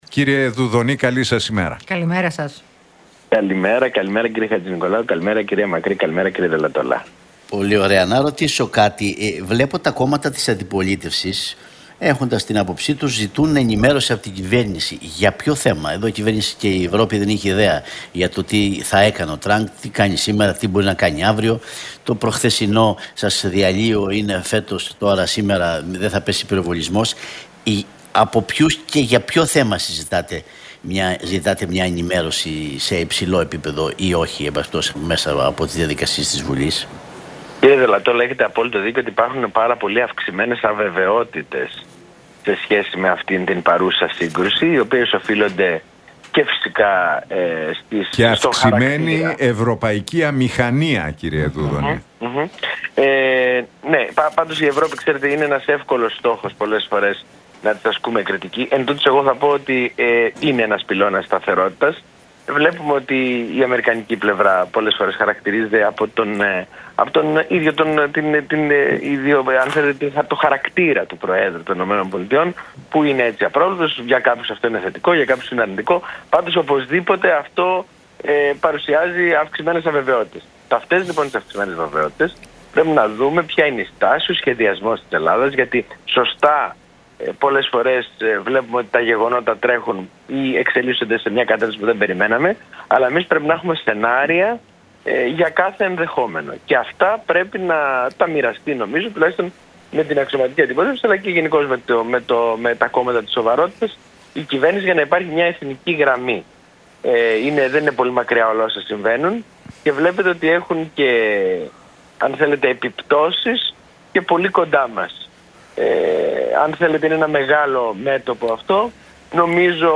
Δουδωνής στον Realfm 97,8 για ελληνοτουρκικά: Ασκείται μία πολιτική προσωπικής προβολής από την κυβέρνηση χωρίς σαφή στόχευση